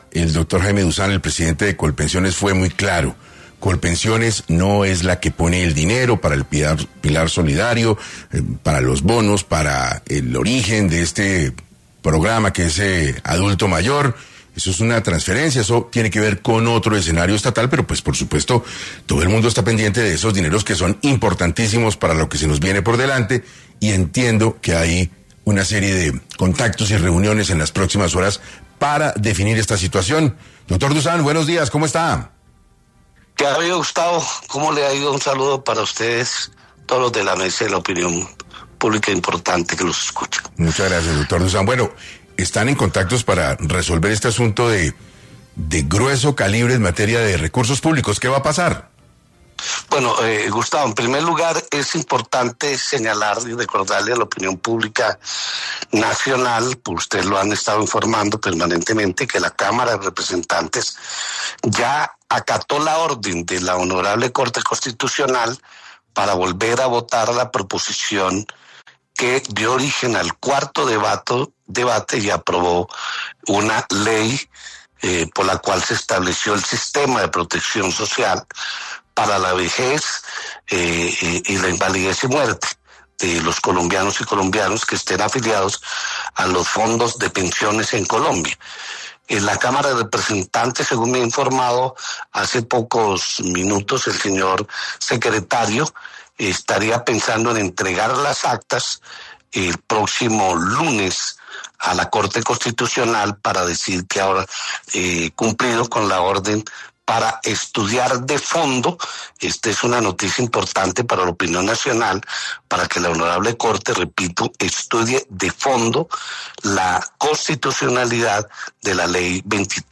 Jaime Dussan, presidente de Colpensiones habló sobre cómo empezará a aplicarse la reforma pensional en Colombia